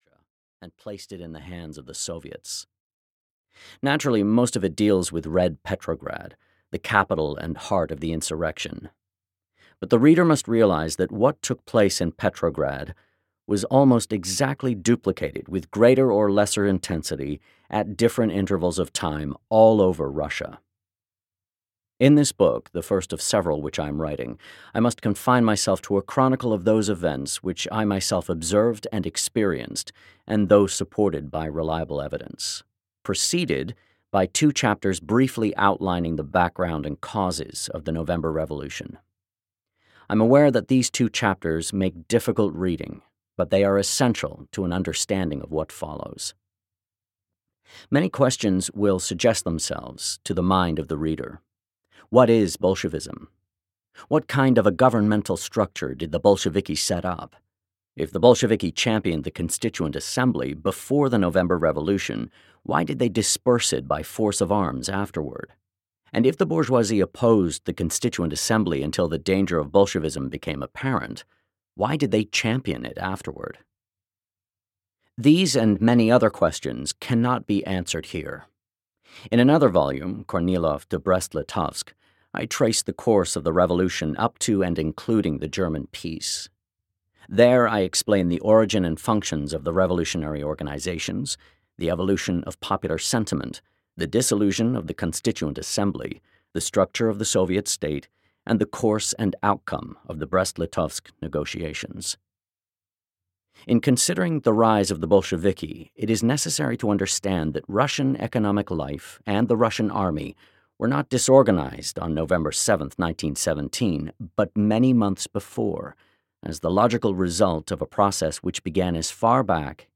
Ten Days that Shook the World (EN) audiokniha
Ukázka z knihy